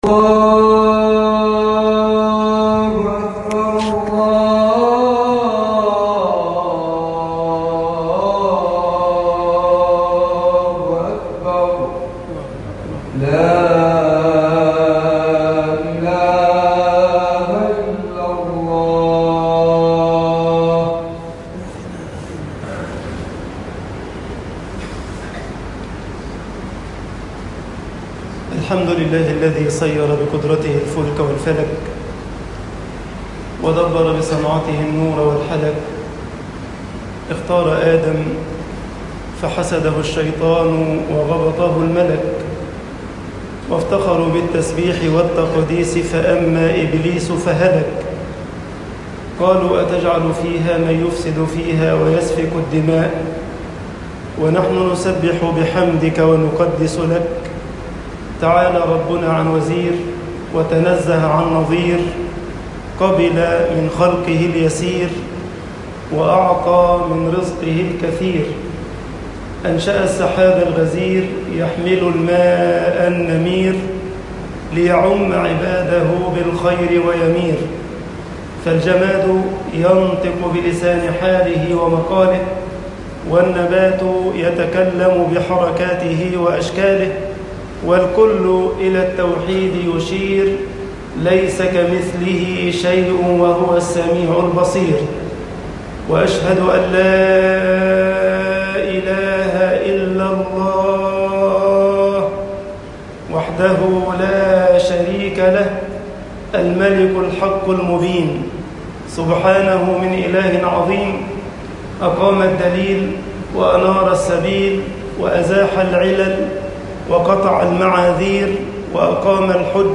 خطب الجمعة - مصر من هدايات القرآن طباعة البريد الإلكتروني التفاصيل كتب بواسطة